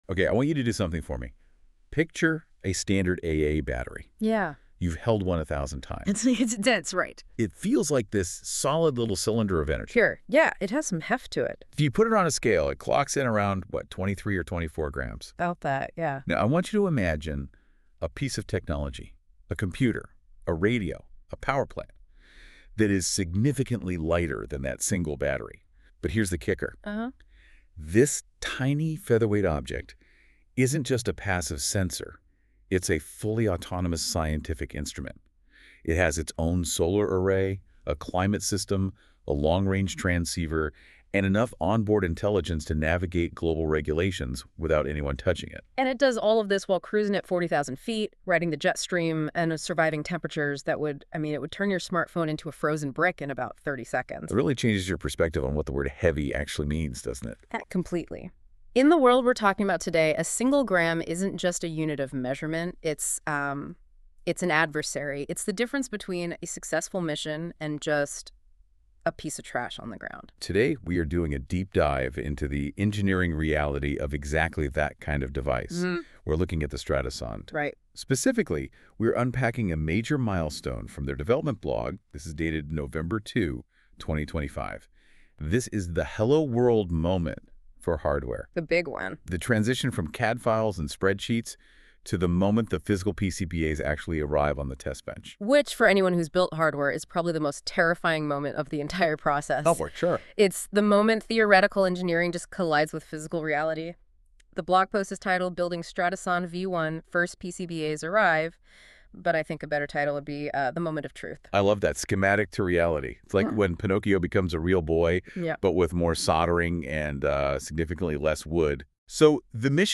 🎧 Listen to this article: NotebookLM Podcast An AI-generated audio discussion created by Google’s NotebookLM